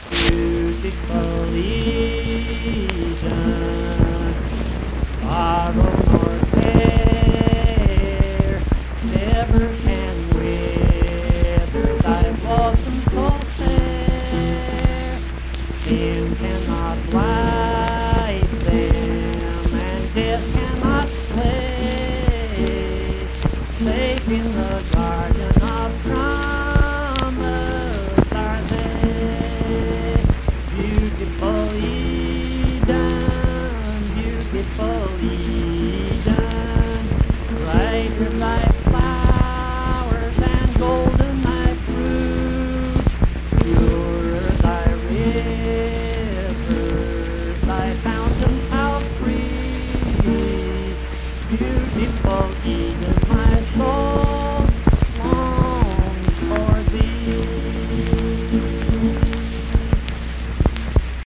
Accompanied (guitar) and unaccompanied vocal music
Verse-refrain 1(4)&R(4). Performed in Mount Harmony, Marion County, WV.
Hymns and Spiritual Music
Voice (sung)